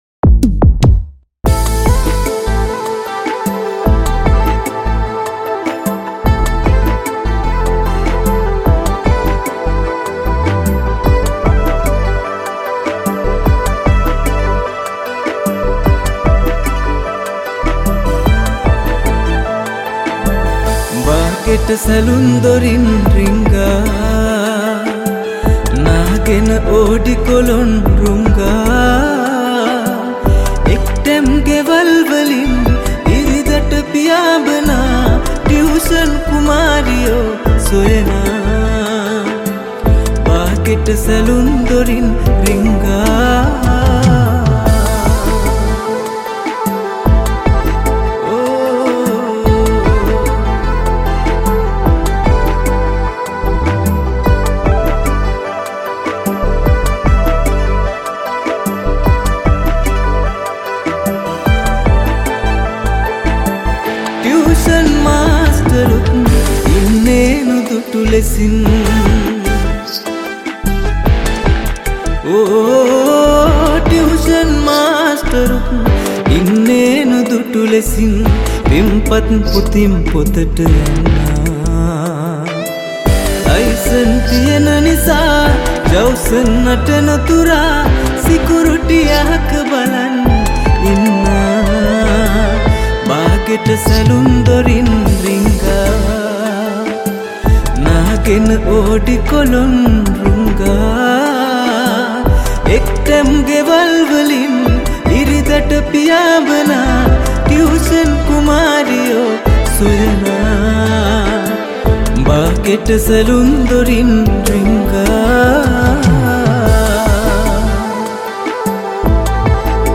Cover Song Mp3 Download